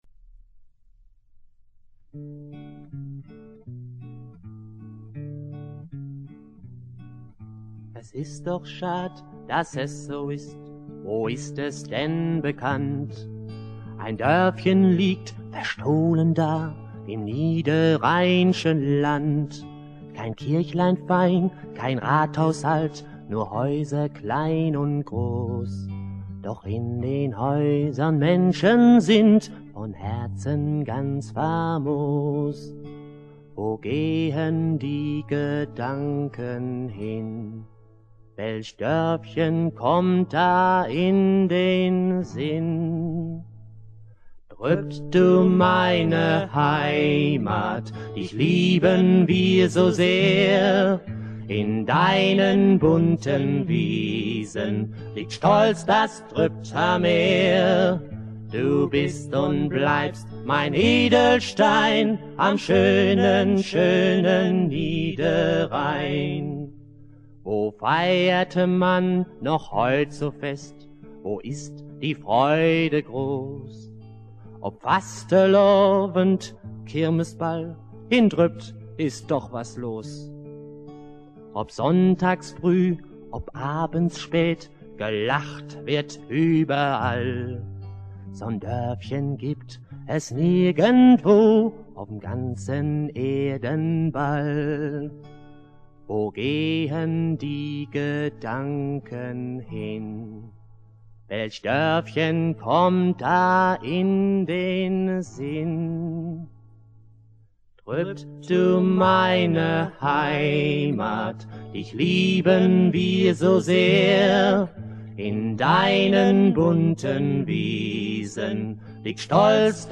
Gesang und Gitarre